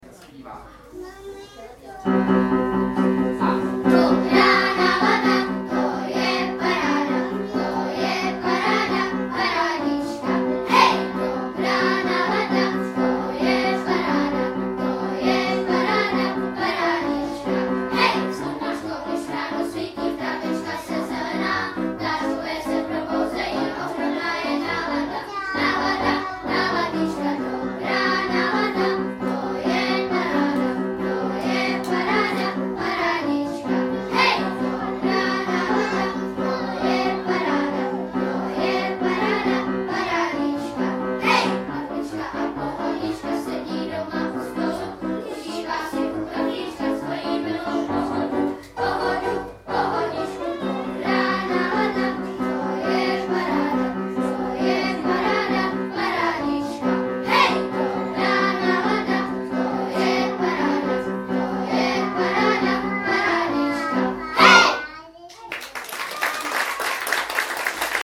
KOUZELNÝ SVĚT ARTUŠE SCHEINERA – Městská knihovna Dobříš čtvrtek 24.10.2013 od 17 hodin
Hudební doprovod – hned 3 slavná hudební tělesa: vokální soubor Kolorit, dětský pěvecký soubor Jeřabinky a Kopretiny !!!